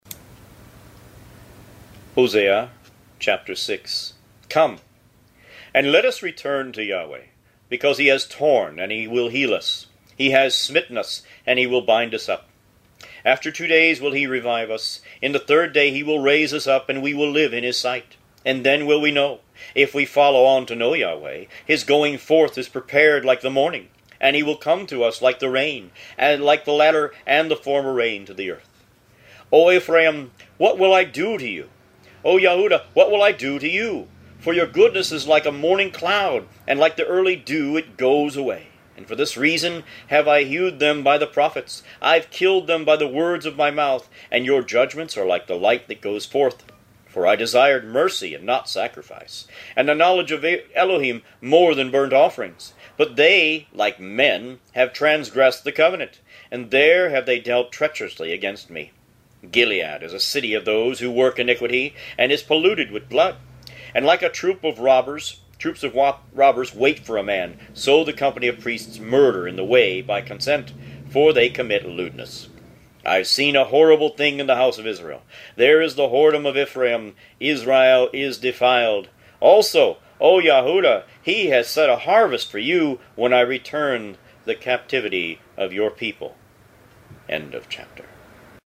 Root > BOOKS > Biblical (Books) > Audio Bibles > Tanakh - Jewish Bible - Audiobook > 28 Hosea